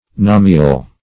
nomial - definition of nomial - synonyms, pronunciation, spelling from Free Dictionary Search Result for " nomial" : The Collaborative International Dictionary of English v.0.48: Nomial \No"mi*al\, n. [Cf. Binomial .]